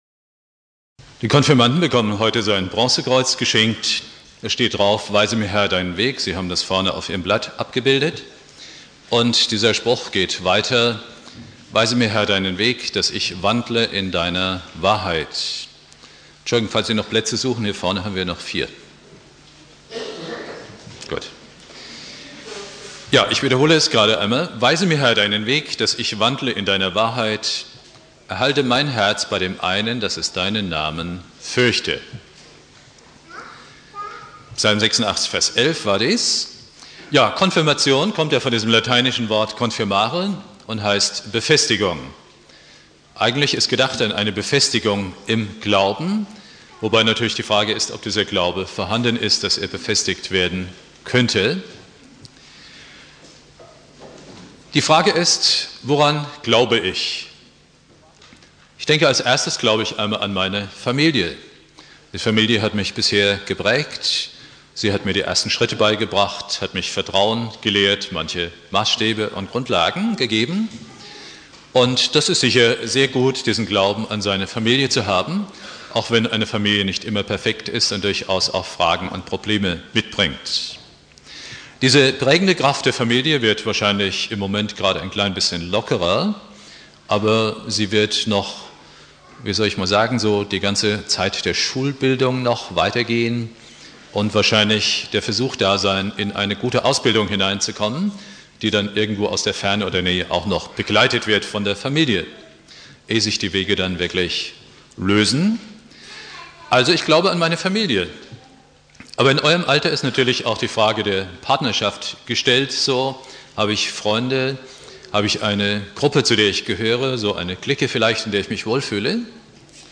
Weise mir, Herr, deinen Weg (Konfirmation Obertshausen) Bibeltext: Psalm 86,11 Dauer